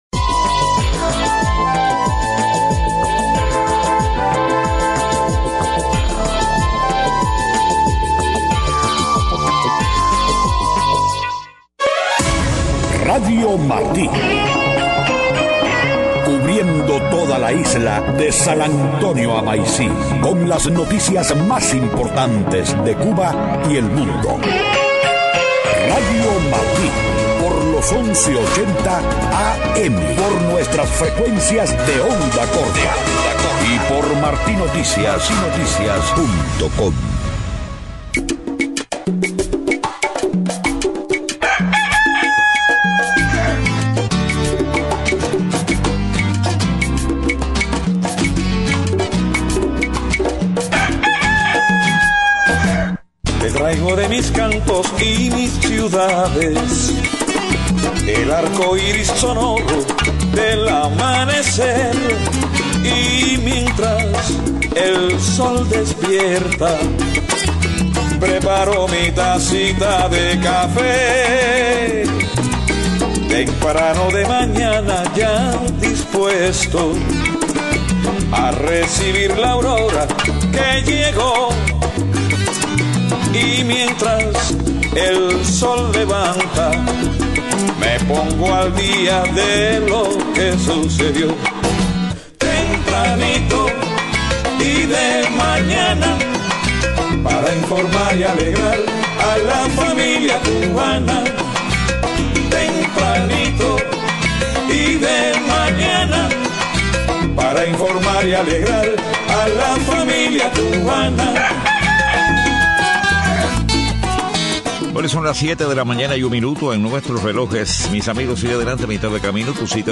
Estado del tiempo. Deportes.